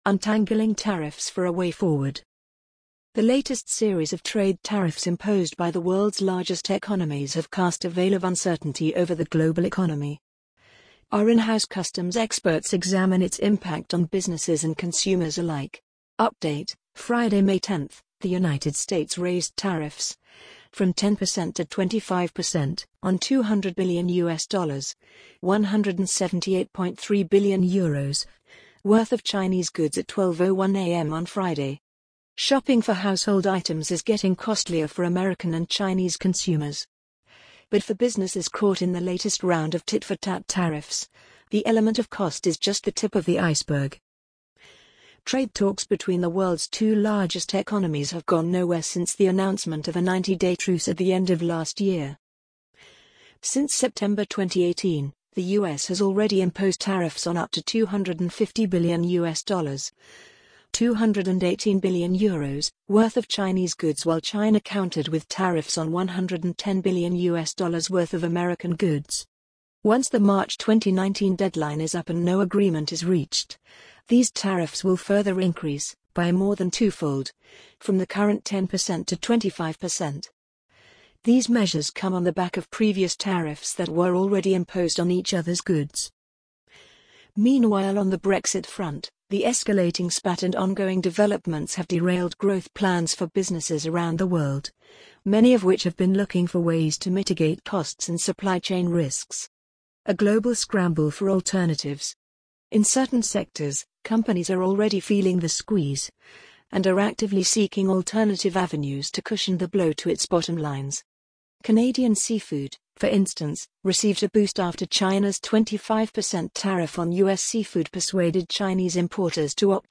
amazon_polly_2738.mp3